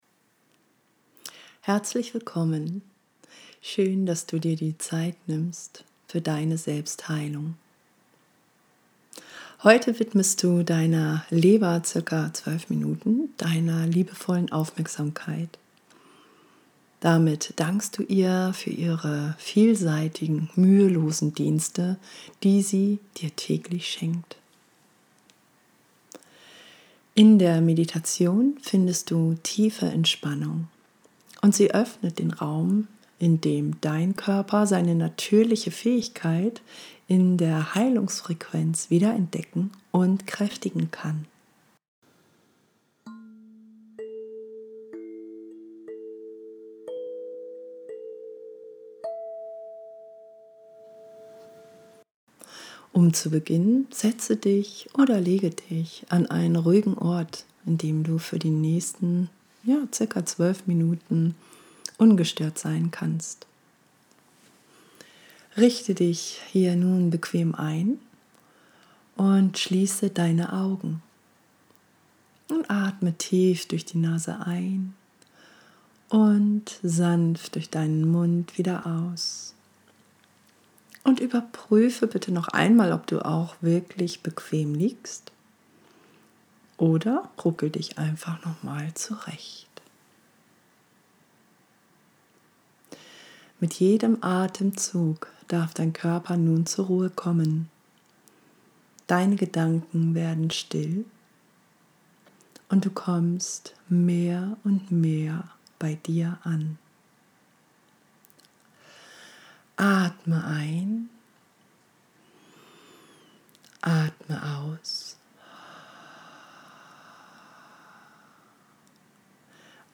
Hier stelle ich Dir kostenlos kurze geführte Meditationen zur Verfügung, die Dich dabei unterstützen, Deine Selbstheilungskräfte zu aktivieren.
Leber-Meditation-Aktiviere-Deine-Selbstheilungskraefte.mp3